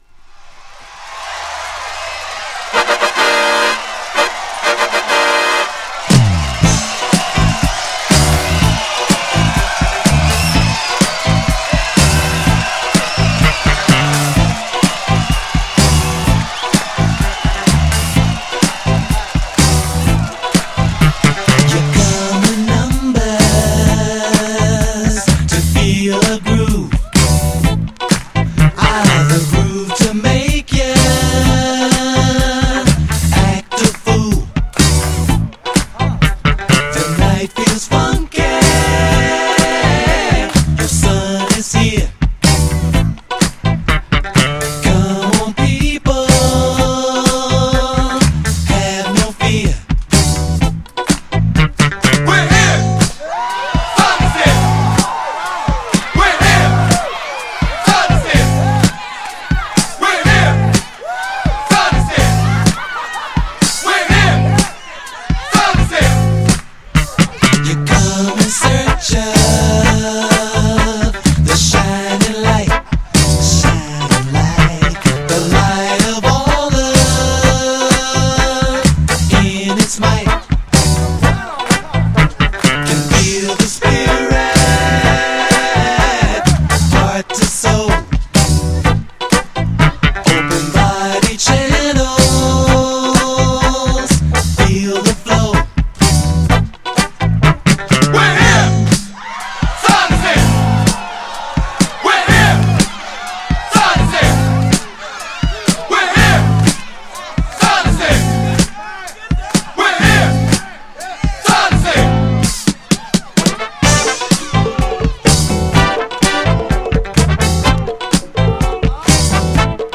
MOIST CLASSICとしても人気の王道クラシックな「ブギウギ」と、ダンサー人気のメロディアスなFUNK
GENRE Dance Classic
BPM 101〜105BPM